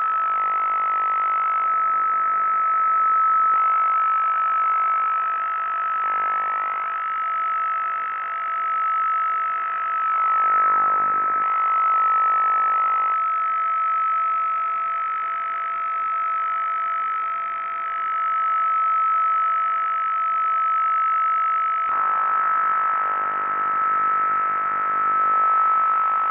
Častým zdrojem rušení, především v obytných budovách, je televize. 5. harmonická řádkového kmitočtu je od DCF vzdálená 625 Hz a tento kmitočet je v rušivém spektru nejsilnější. Televize často vyzařuje mimo rozkladových kmitočtů i video signál, takže rušivé spektrum se mění podle obrazu a při pohybu obrazu mají spektrální čáry násobků snímkového rozkladu svá postranní pásma. Záznam z přijímače DCF-M v režimu BFO, anténa s šířkou pásma 2 KHz, je